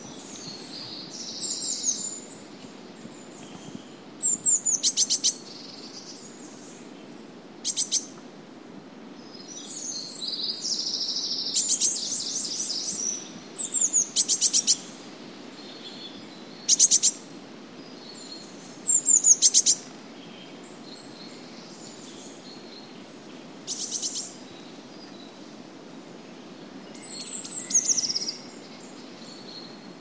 Photos de mésange bleue - Mes Zoazos
mesange-bleue.mp3